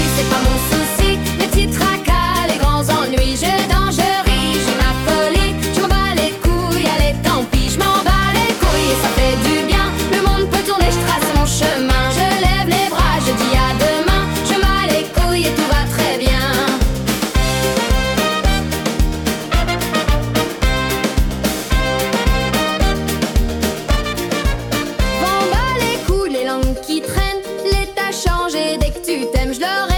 Dance French Pop
Жанр: Поп музыка / Танцевальные